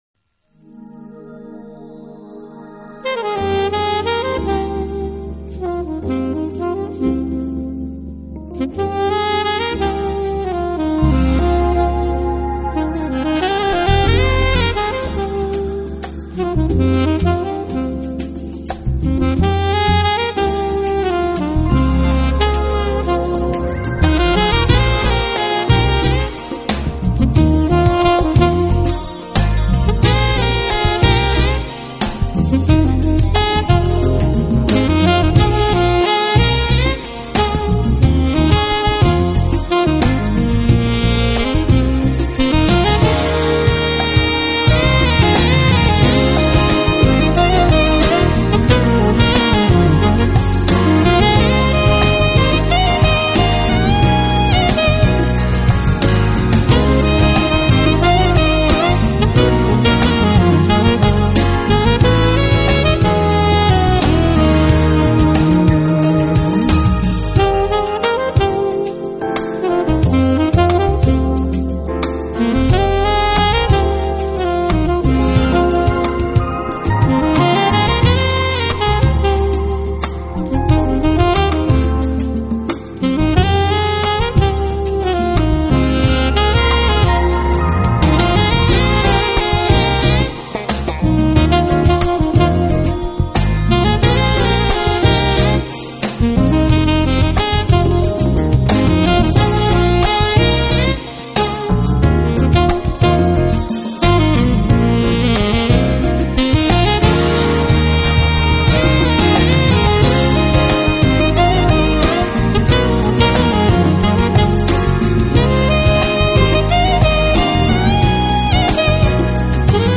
专辑流派： Jazz
风格偏于布鲁斯和流行爵士。